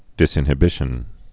(dĭsĭn-hə-bĭshən, -ĭn-ə-, dĭs-ĭn-)